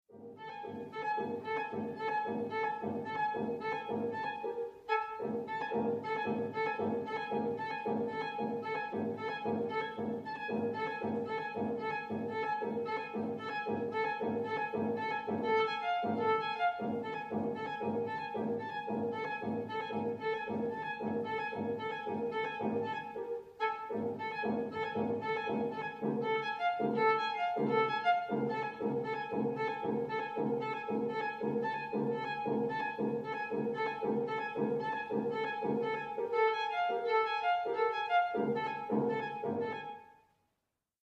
violin / piano